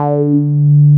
RUBBER D4 M.wav